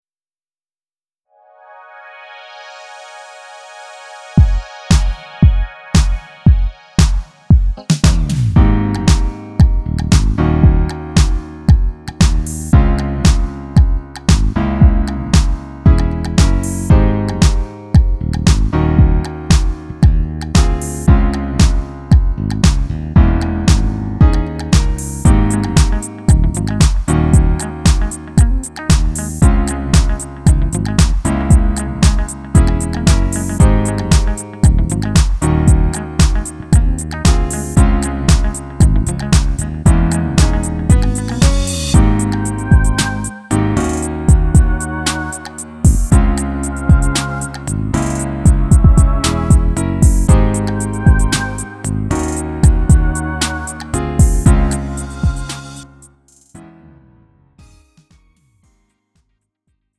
음정 원키 3:21
장르 가요 구분 Lite MR